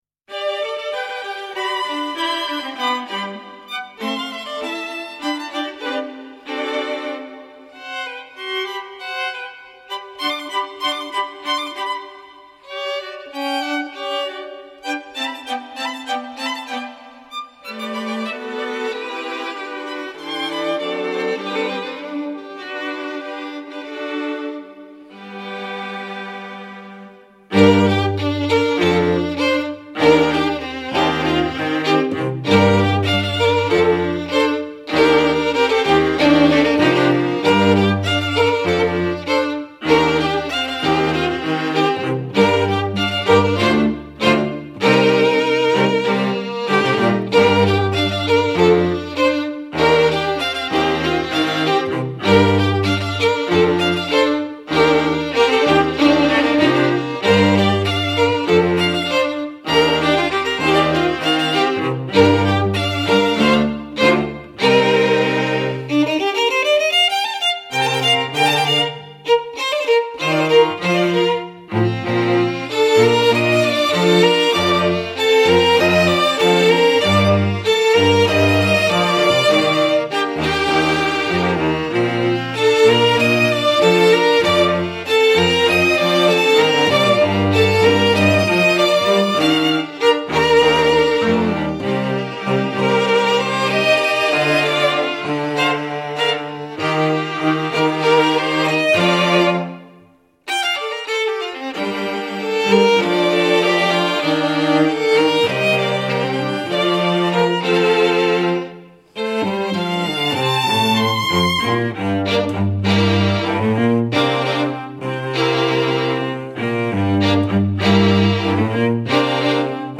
Septet
Septet 20 works by 14 composers Chamber [95%] Choral [5%] Group: Chamber Members: String septet 7 instruments or singers INO Orchestra - String septets - 4 Playlist includes music from genres: String septet
INO Orchestra - String septets - 4.mp3